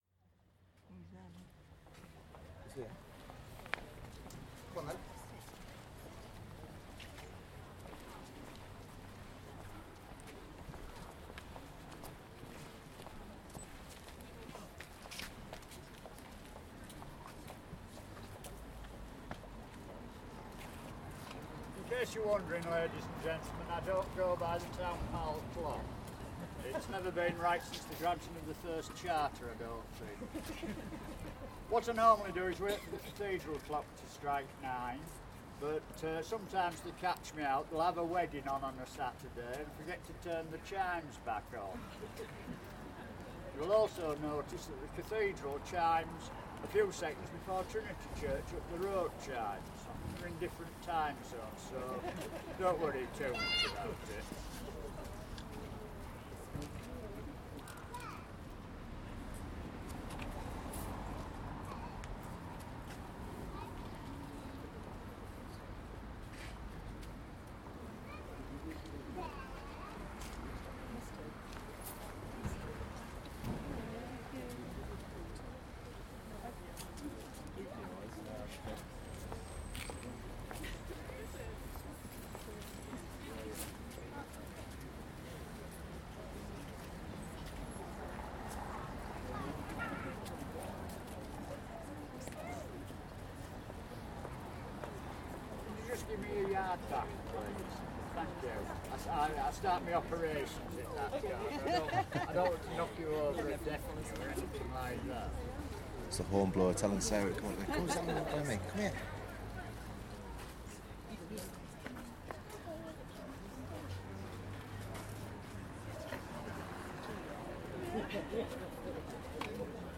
The Ripon Hornblower [stereo]